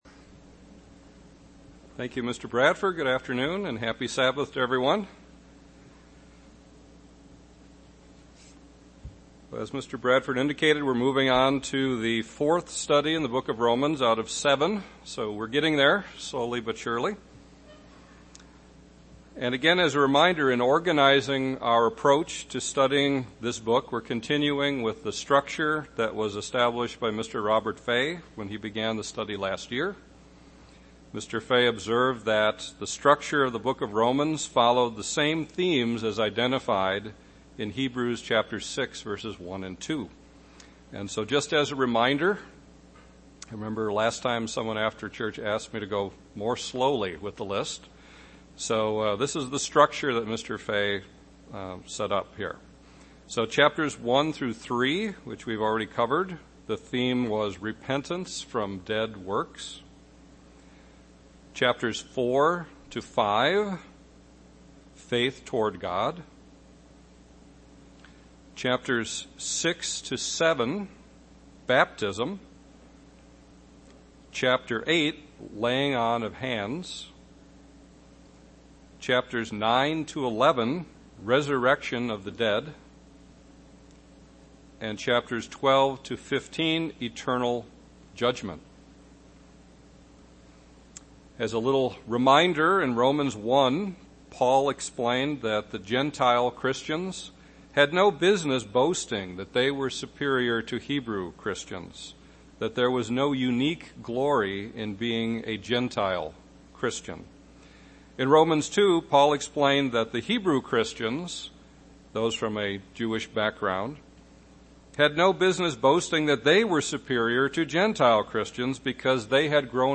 UCG Sermon Romans Studying the bible?